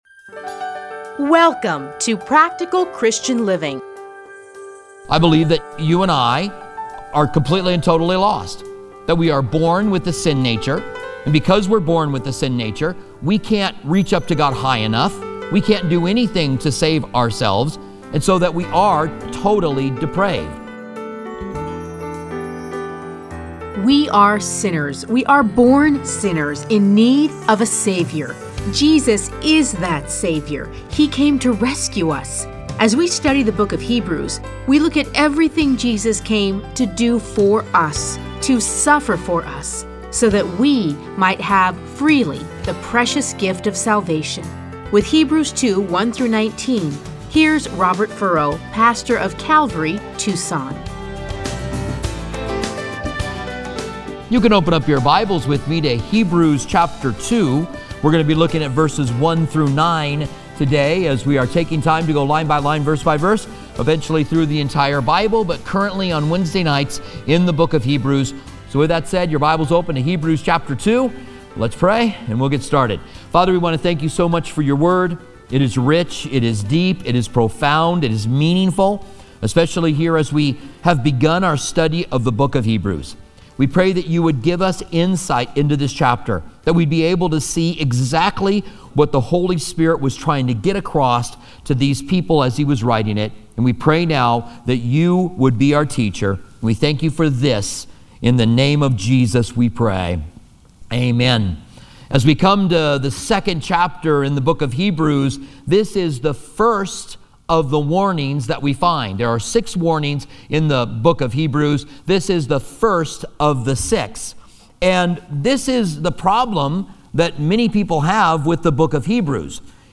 Listen to a teaching from Hebrews 2:1-9.